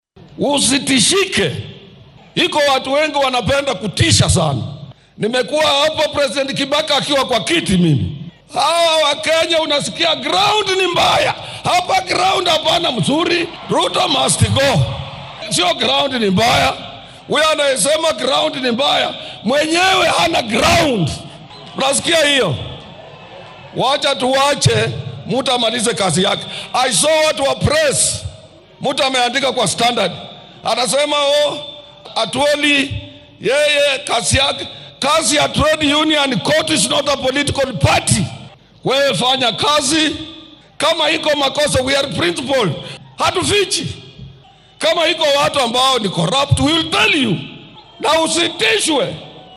Waxaa uu baaqan jeediyay xilli maanta loo dabbaal degayay maalinta adduunka ee shaqaalaha iyo sanadguuradii 60-aad ee ka soo wareegatay aasaaska COTU.